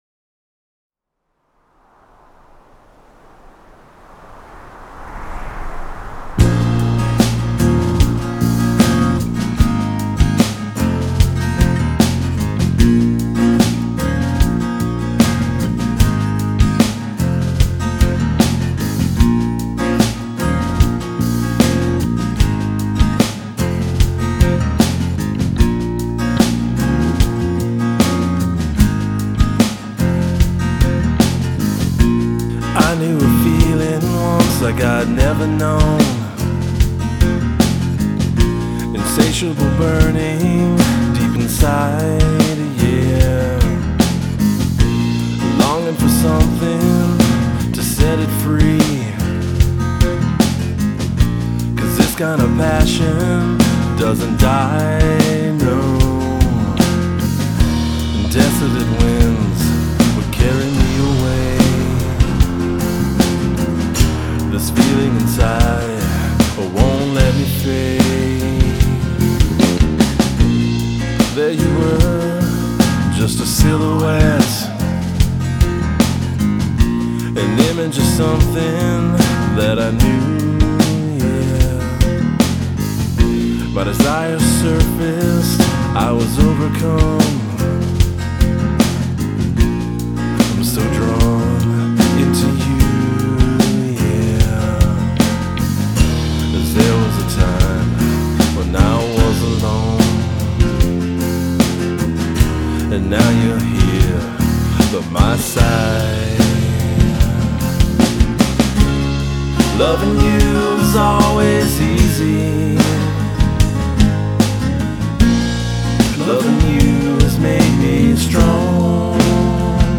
Rock / Pop
Vocals, guitars
Bass, Drums